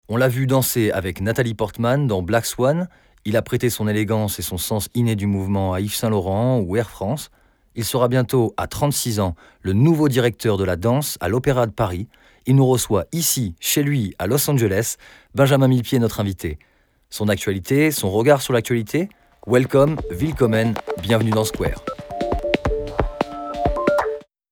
Sprechprobe: Industrie (Muttersprache):
I am a singer and comedian,i am very passionned by voice over, i can do imitations,cartoon also adapt my voice tone to the customers requests.